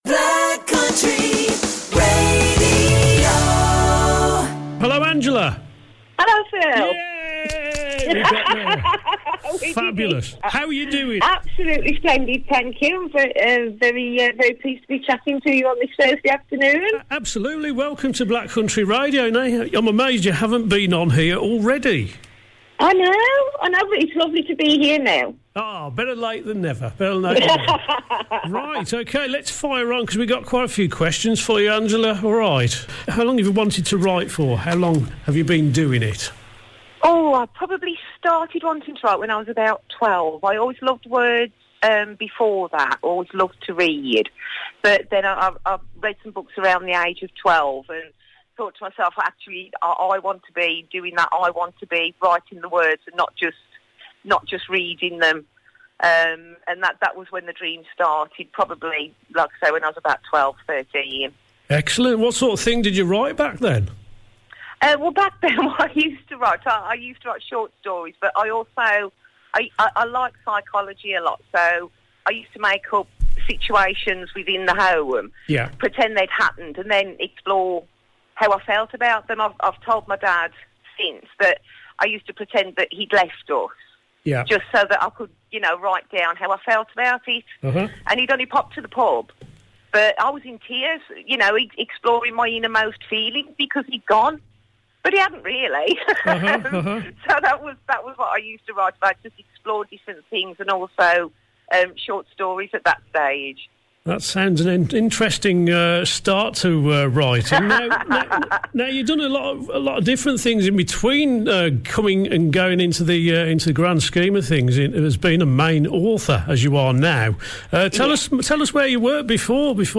Interview with Angela Marsons on Black Country Radio